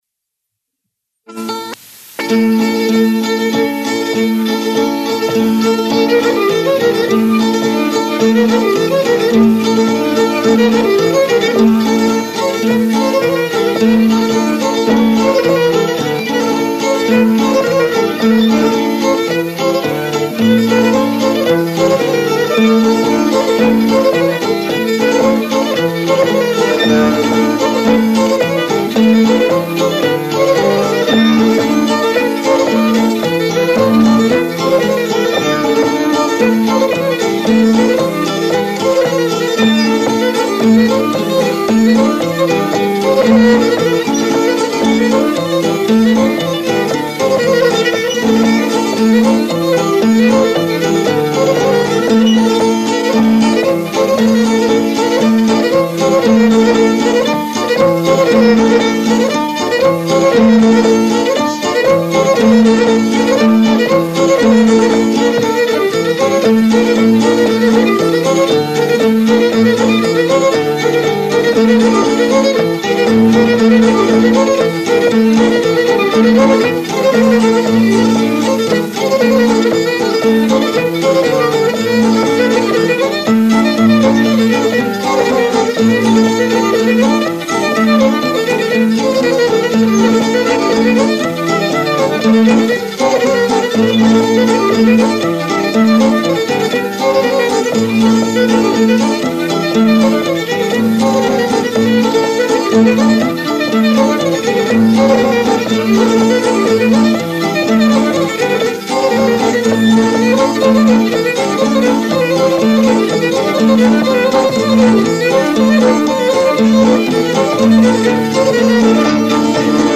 ΜΟΥΣΙΚΗ ΑΠΟ ΤΗΝ ΠΑΡΑΔΟΣΗ ΤΗΣ ΑΝΑΤΟΛΙΚΗΣ ΚΡΗΤΗΣ
6. Κοντυλιές στη La minore (με ασκομαντούρα)